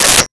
Telephone Receiver Down 01
Telephone Receiver Down 01.wav